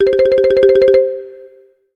ringtone.ogg